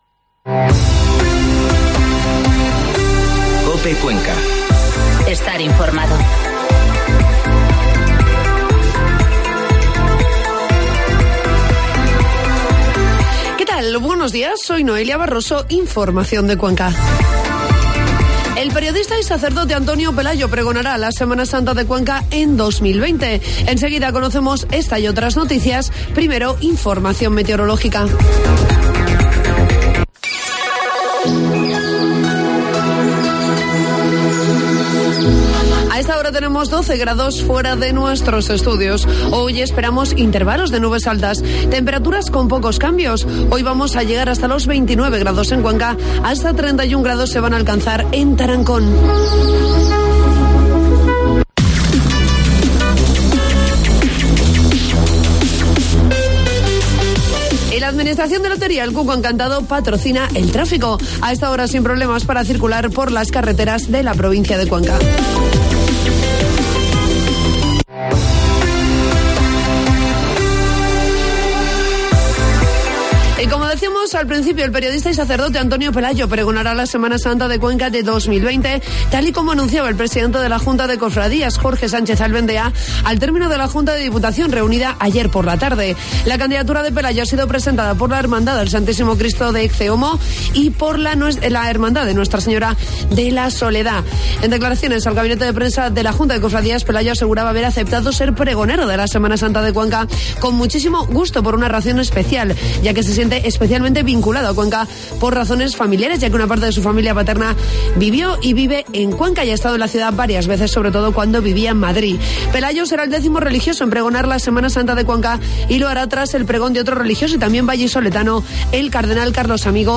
Informativo matinal COPE Cuenca 8 de octubre